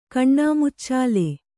♪ kaṇṇāmuccāle